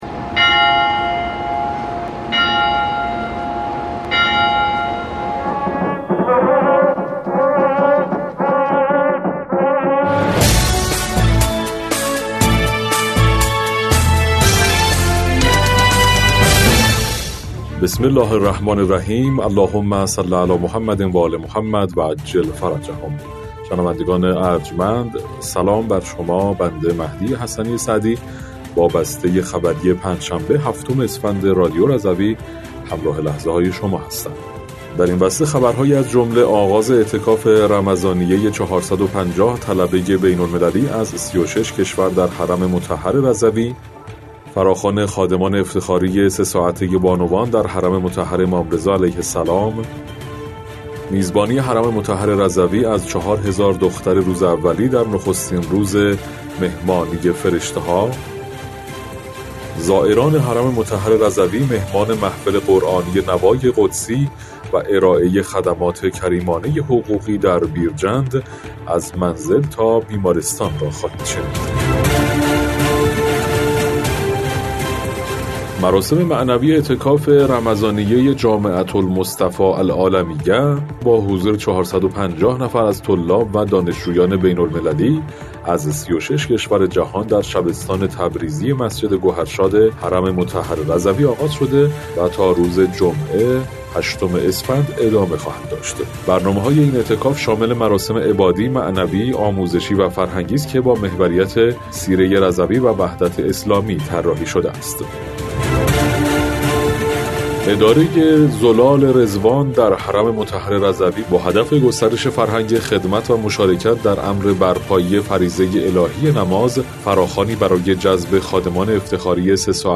بسته خبری ۷ اسفند ۱۴۰۴ رادیو رضوی؛